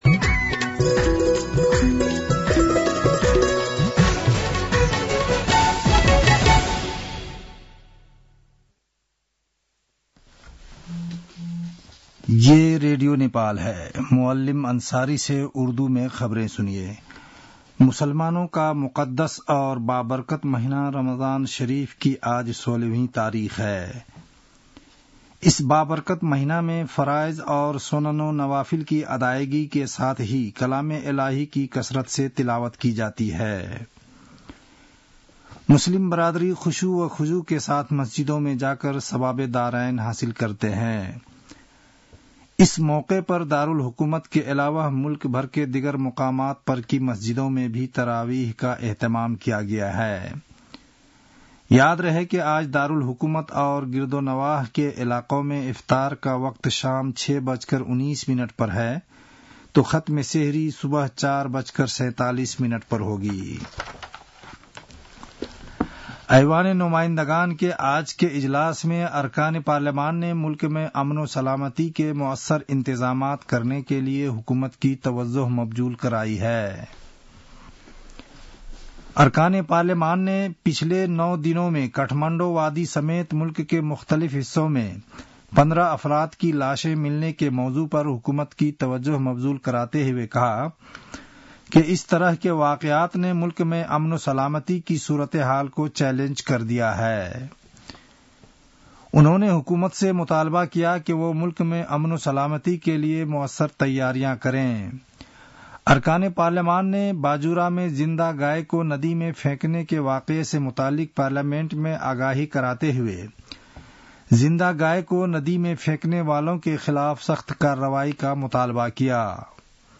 An online outlet of Nepal's national radio broadcaster
उर्दु भाषामा समाचार : ४ चैत , २०८१